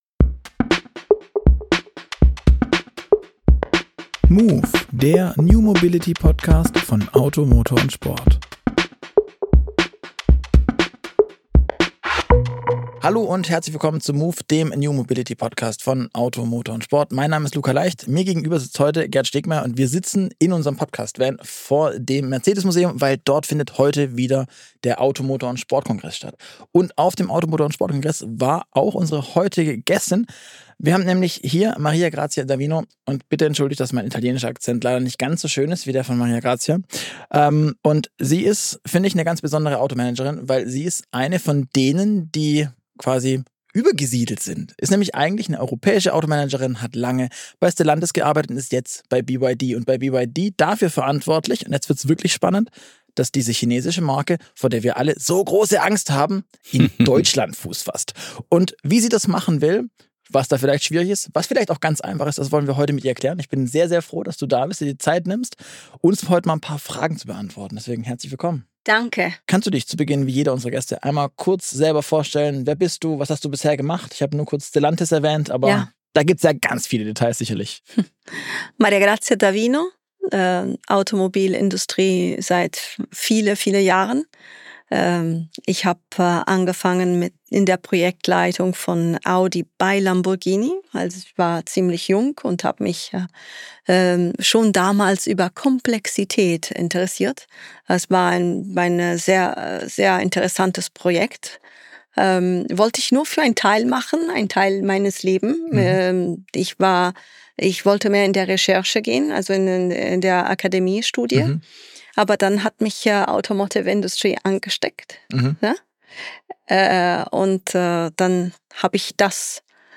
Ein offenes Gespräch über Unternehmenskultur, Markteintritt und die Zukunft des Automobilgeschäfts zwischen Europa und China.